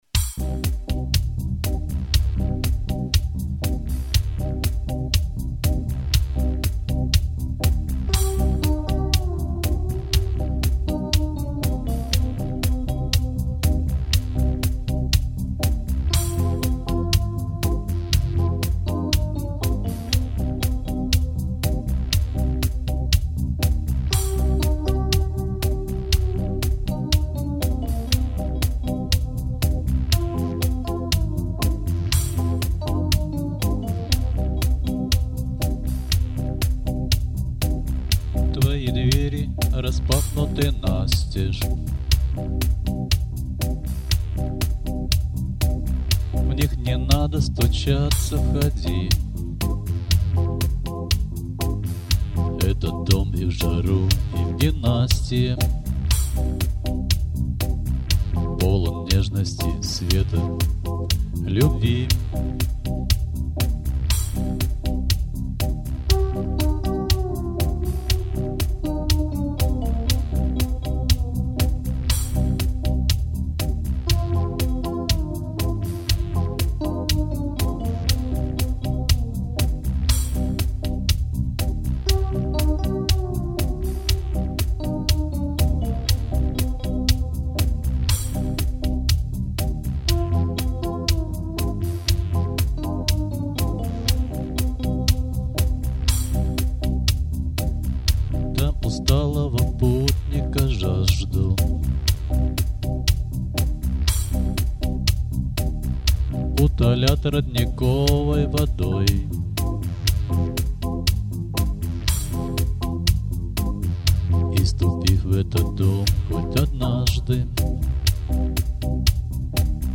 • Жанр: Легкая
Ну вот и  первая песня в моём исполнении....